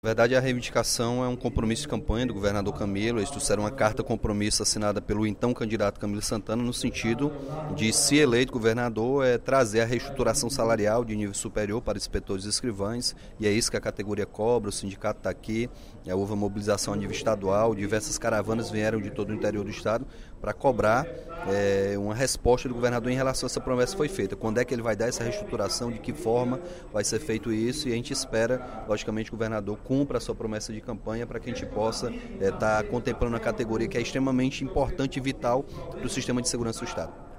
O deputado Capitão Wagner (PR) fez pronunciamento, nesta quinta-feira (22/10), durante o primeiro expediente da sessão plenária, para pedir que o governador Camilo Santana cumpra o compromisso firmado com o Sindicato dos Policiais Civis quando ainda postulava ao Executivo do Estado.